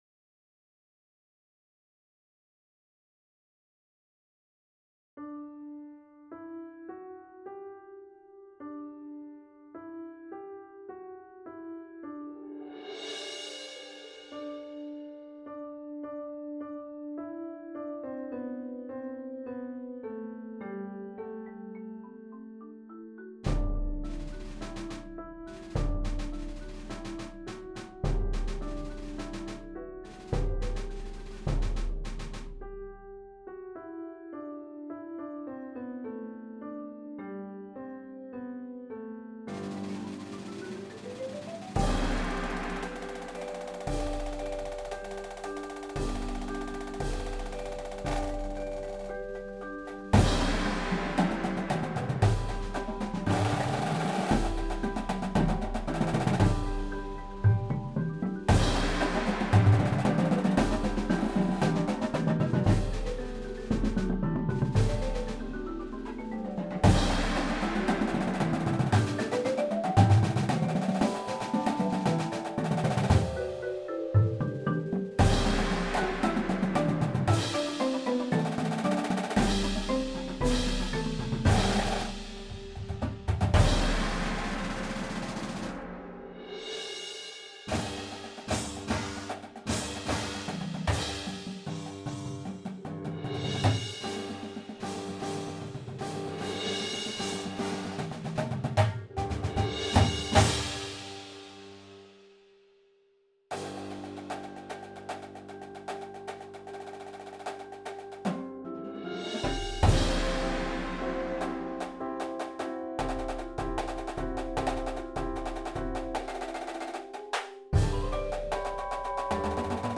Patriotic themed Indoor Percussion Show